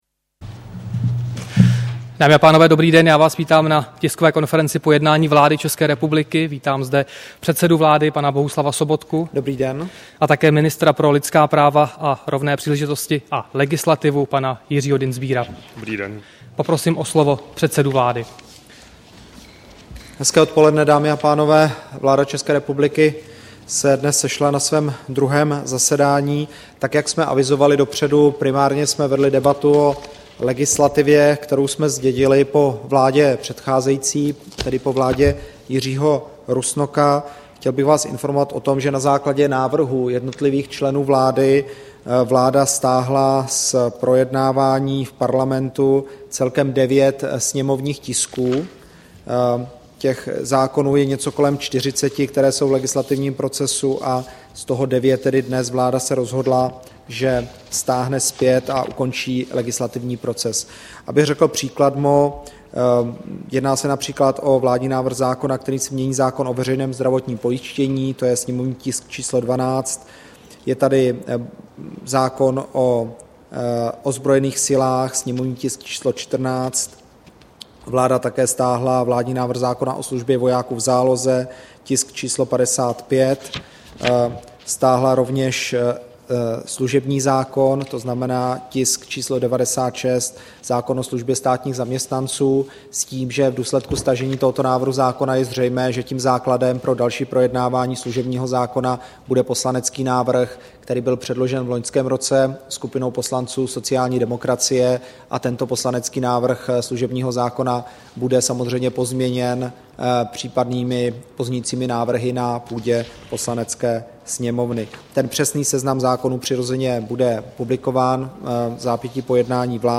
Tisková konference po jednání vlády, 3. února 2014